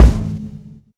Live_kick_a.wav